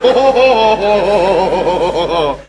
Worms speechbanks
laugh.wav